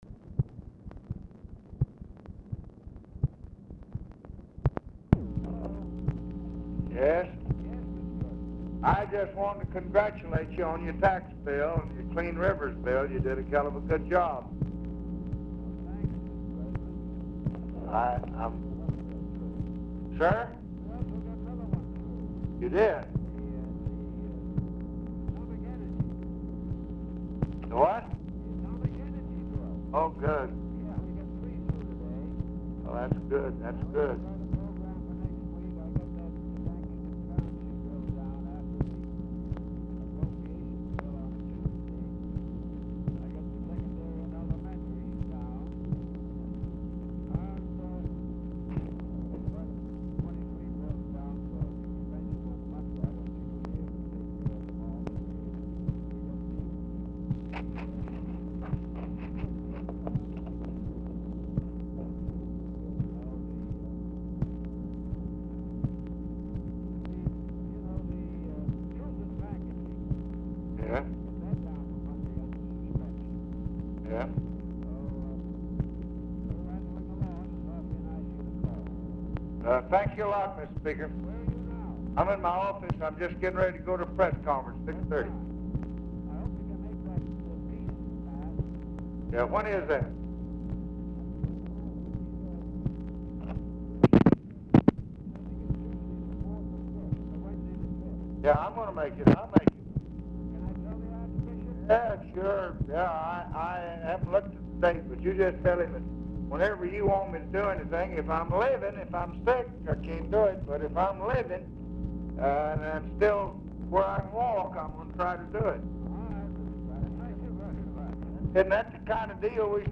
Telephone conversation # 10858, sound recording, LBJ and JOHN MCCORMACK, 9/30/1966, 6:20PM | Discover LBJ
POOR SOUND QUALITY; MCCORMACK IS ALMOST INAUDIBLE
Format Dictation belt
Location Of Speaker 1 Oval Office or unknown location